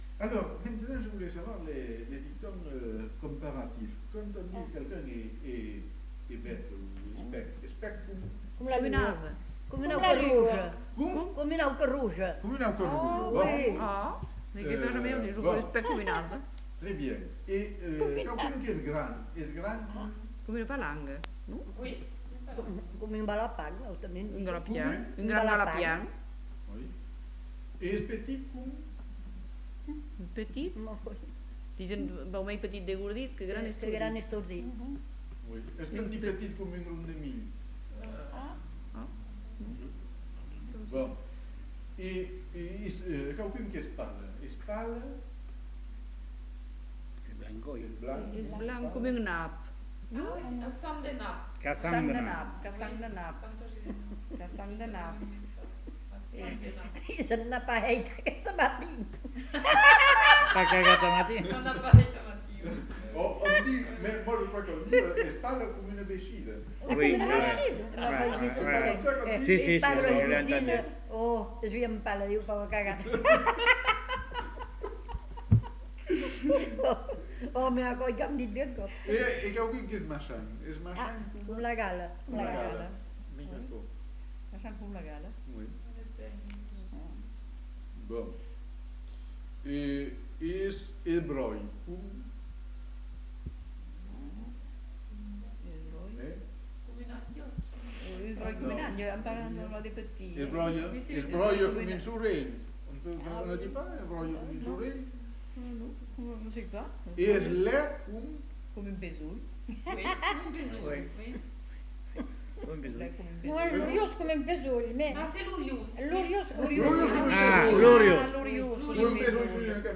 Lieu : Uzeste
Genre : forme brève
Type de voix : voix de femme Production du son : parlé